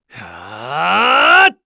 Lusikiel-Vox_Casting1_kr.wav